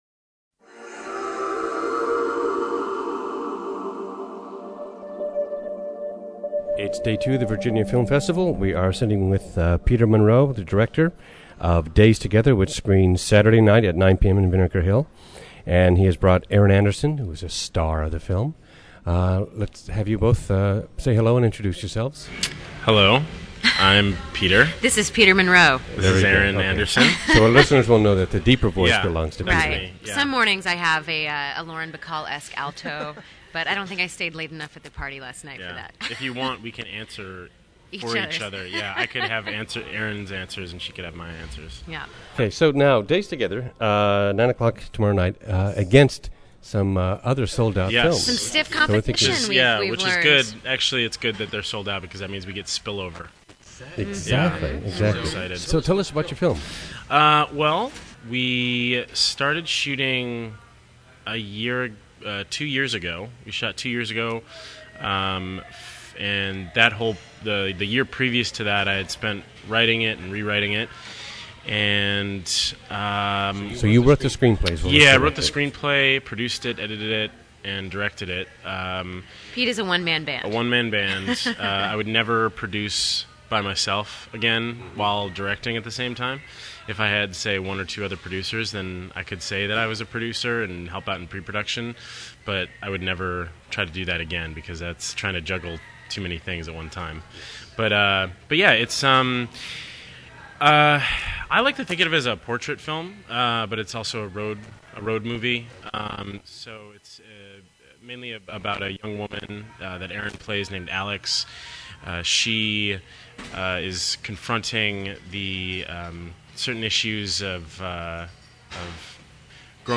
On day two of the Virginia Film Festival